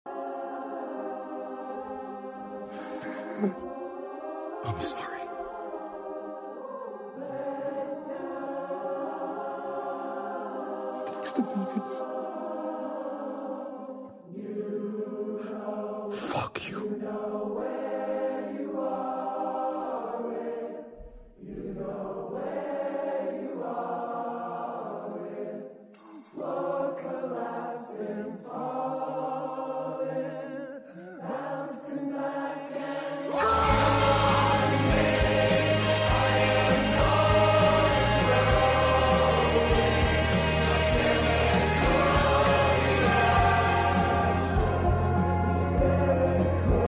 (Choir Version)